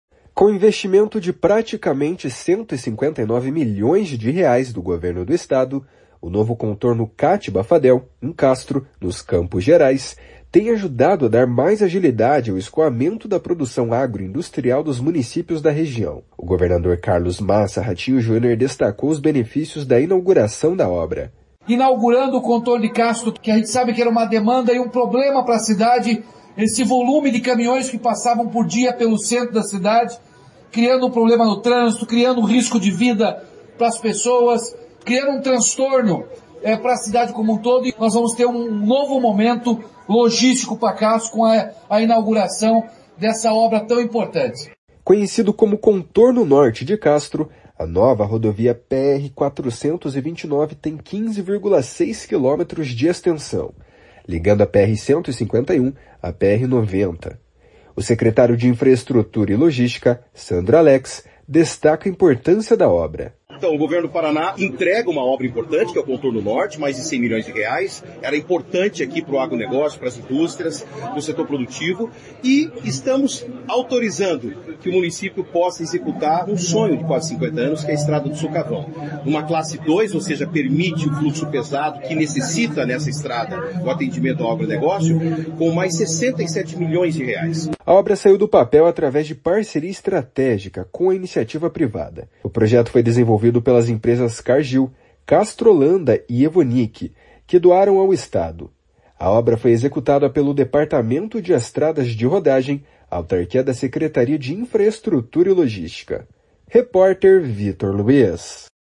Com investimento de praticamente 159 milhões de reais do Governo do Estado, o novo Contorno Kátiba Fadel, em Castro, nos Campos Gerais, tem ajudado a dar mais agilidade ao escoamento da produção agroindustrial dos municípios da região. O governador Carlos Massa Ratinho Junior destacou os benefícios da inauguração da obra. // SONORA RATINHO JUNIOR //
O secretário de Infraestrutura e Logística, Sandro Alex, destaca a importância da obra.